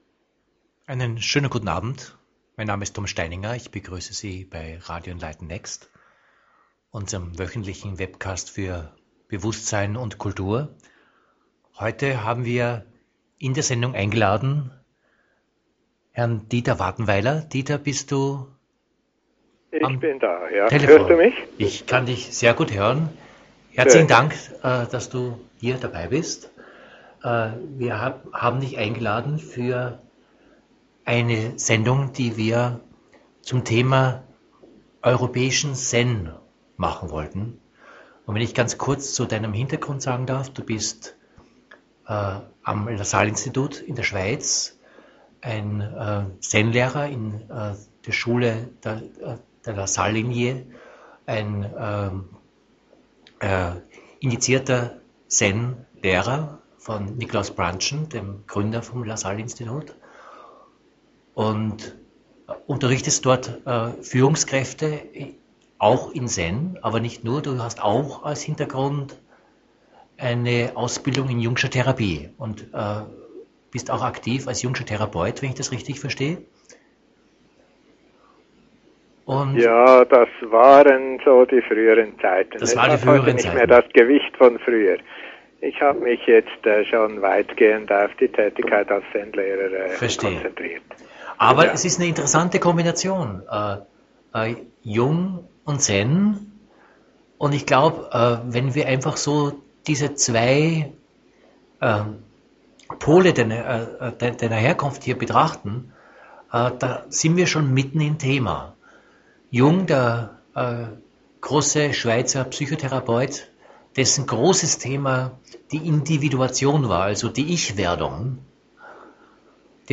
Radio-Interview